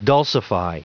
Prononciation du mot dulcify en anglais (fichier audio)
Prononciation du mot : dulcify